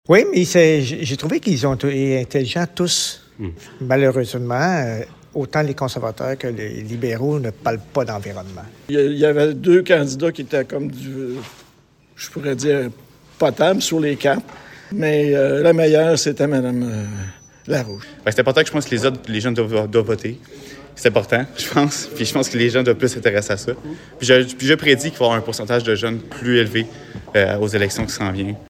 Granby, vox-pop candidats Shefford, 23.04.25_Vox-pop, clip_1